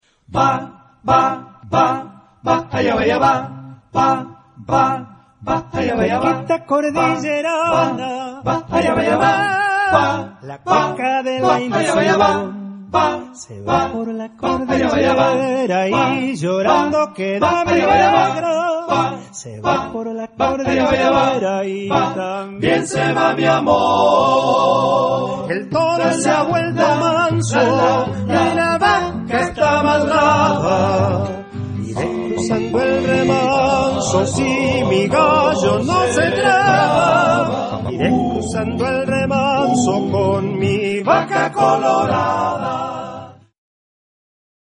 Charakter des Stückes: liebevoll ; niedlich
Chorgattung: TTBBB  (5 Männerchor Stimmen )